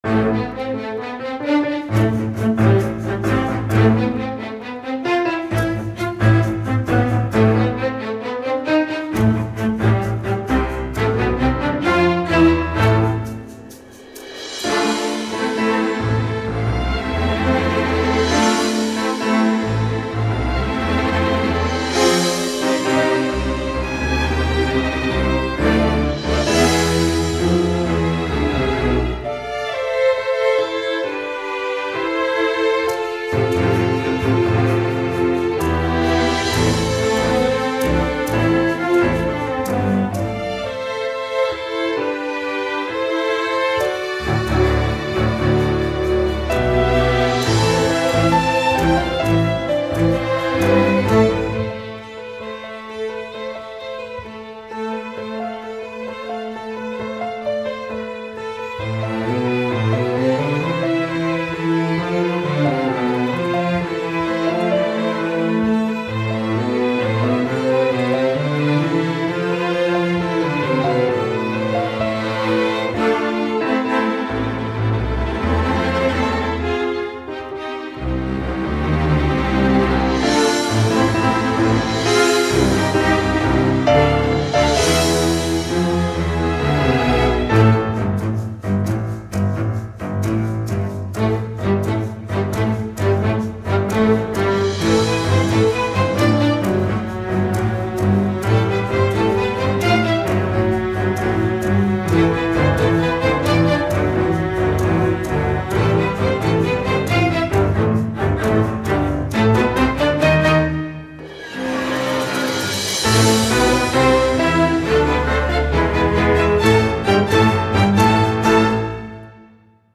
1st percussion part:
Piano accompaniment part: